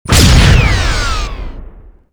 otherShieldsHit.wav